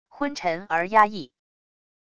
昏沉而压抑wav音频